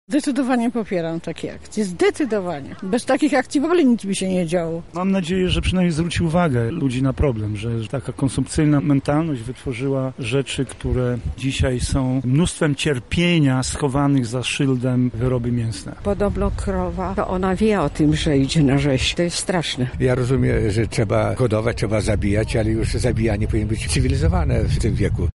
Wspólnie z zainteresowanymi trzymali plansze oraz klatki filmowe i rozdawali ulotki informacyjne przed budynkiem Poczty Głównej na Placu Litewskim.
Co o tym sądzą lublinianie? Pytał o to nasz reporter: